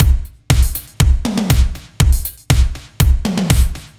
Index of /musicradar/french-house-chillout-samples/120bpm/Beats
FHC_BeatB_120-02.wav